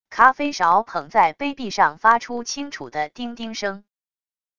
咖啡勺捧在杯壁上发出清楚的叮叮声wav音频